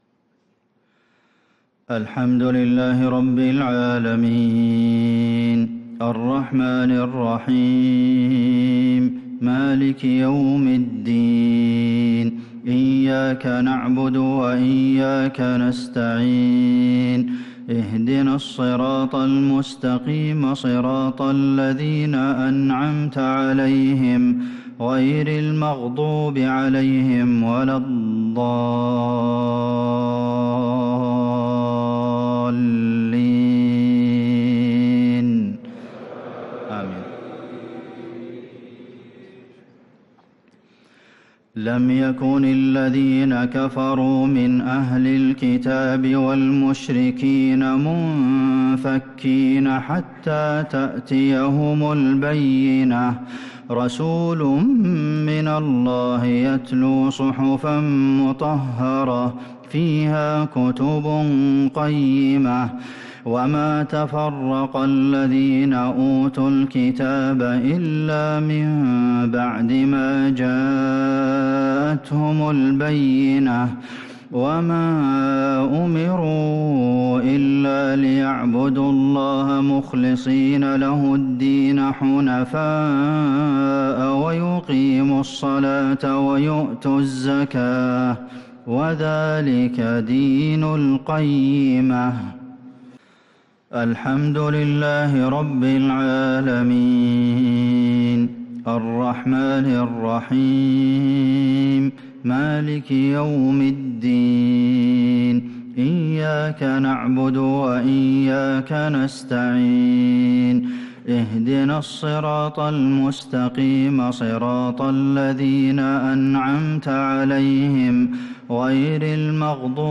صلاة المغرب ٩-٧-١٤٤٦هـ | سورة البينة كاملة | Maghrib prayer from Surah al-Bayyinah |9-1-2025 > 1446 🕌 > الفروض - تلاوات الحرمين